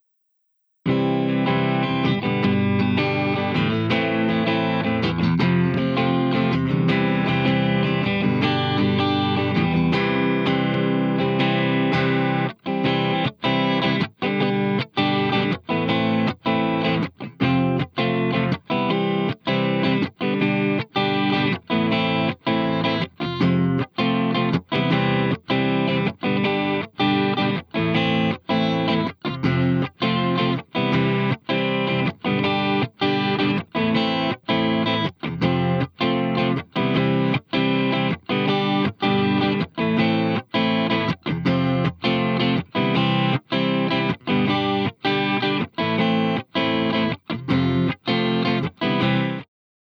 ThreeD is our versatile and simple mono-to-stereo widener and stereo-to-stereo enhancement effect.
Add stereo width without adding reverb.
Guitar
ThreeD_Guitar.wav